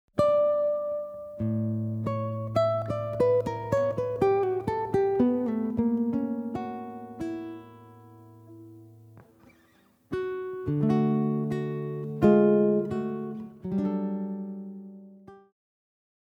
Bring a fresh, modern sound to this classic carol
jazz-inspired solo guitar arrangement